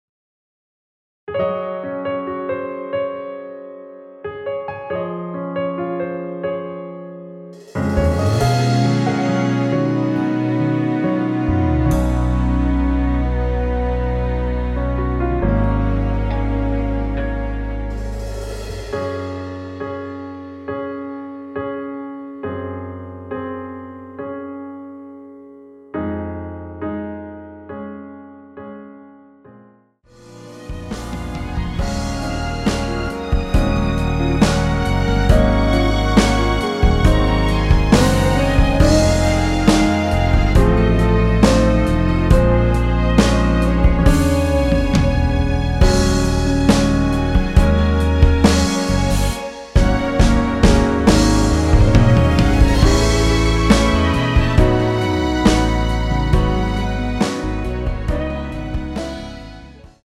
원키에서(-2)내린 1절후 후렴으로 진행 되는 MR입니다.
Db
앞부분30초, 뒷부분30초씩 편집해서 올려 드리고 있습니다.
중간에 음이 끈어지고 다시 나오는 이유는